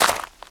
footstep.ogg